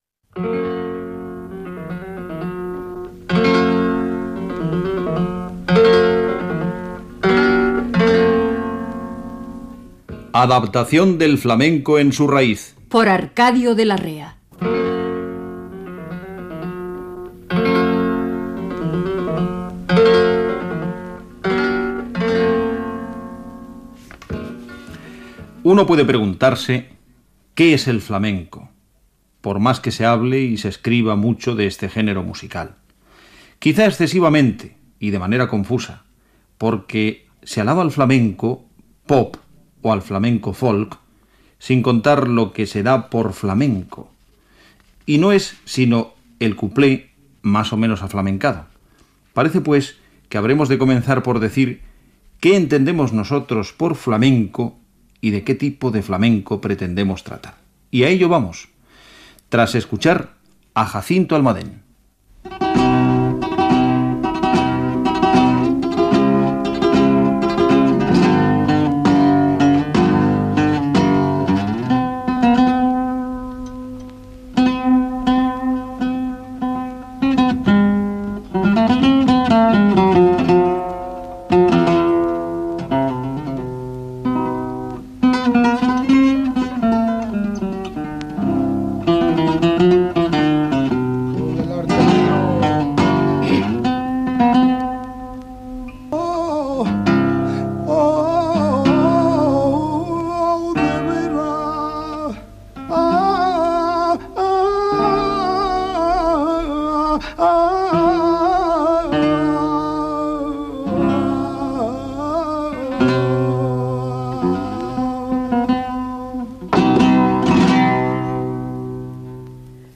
Careta del programa, explicació sobre què és el flamenc, tema musical, paraules del poeta Manuel Machado, dos temes musicals
Musical
FM